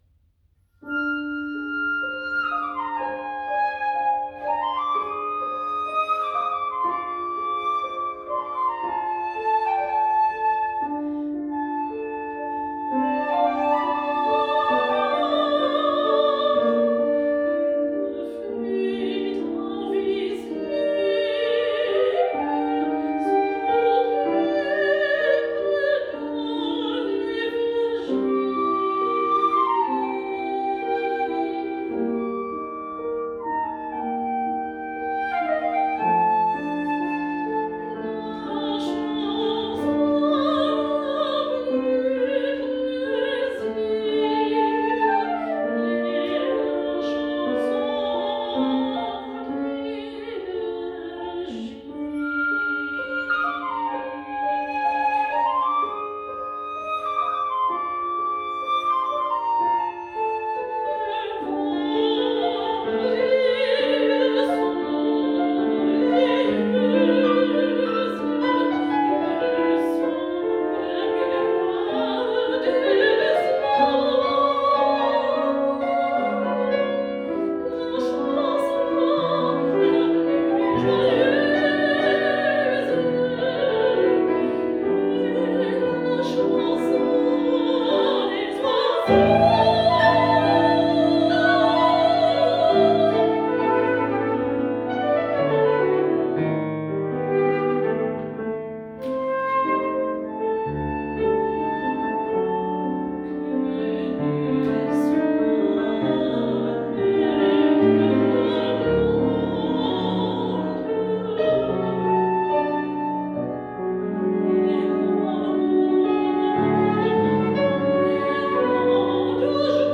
Flöte
Sopran
Klavier